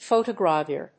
音節pho・to・gra・vure 発音記号・読み方
/fòʊṭəgrəvjˈʊɚ(米国英語), f`əʊtəgrəvjˈʊə(英国英語)/